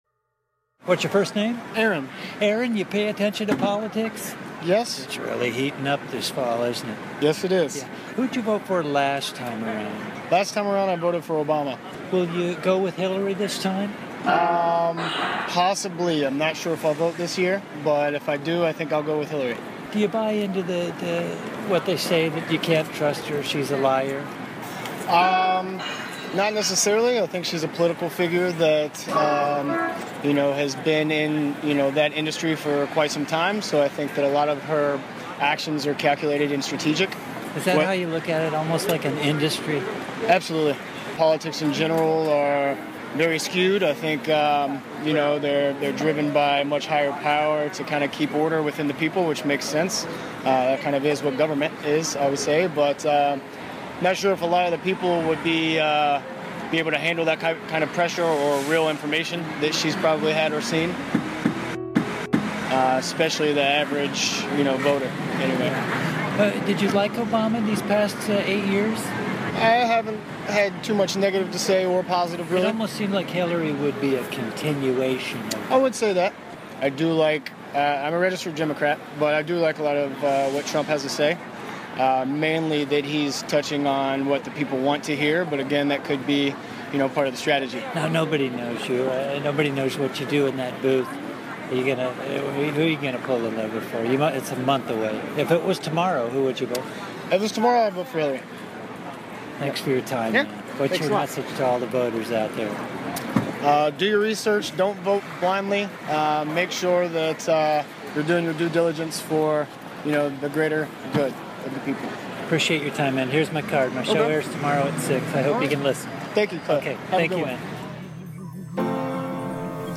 Set: Phil Anselmo speaks at Loyola